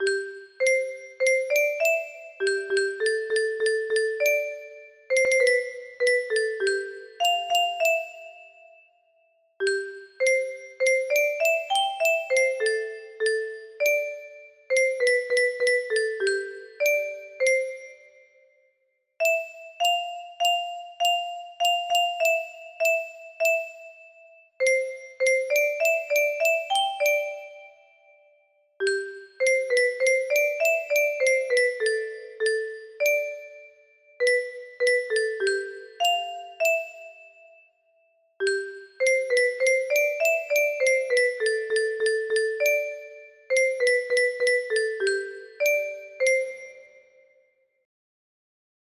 Traditional - Sarie Marais music box melody